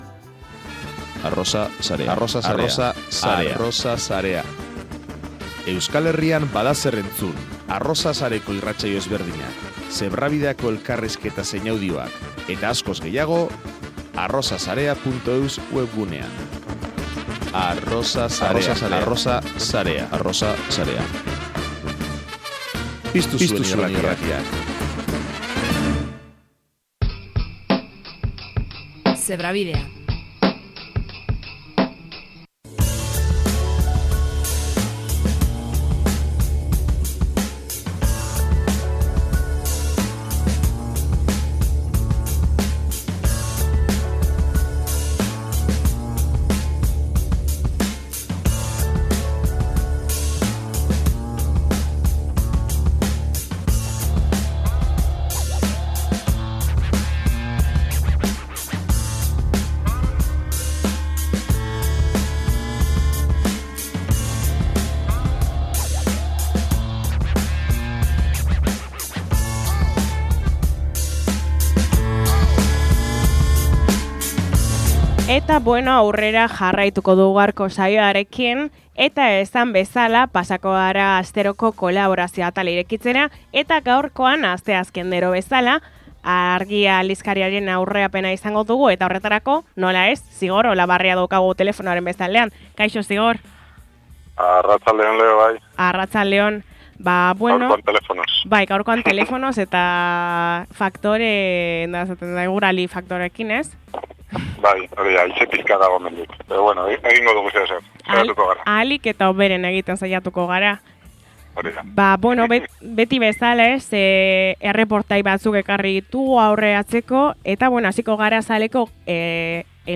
elkarrizketa, Muga jaialdia eta ‘Kroazia azken muga’ erreportajea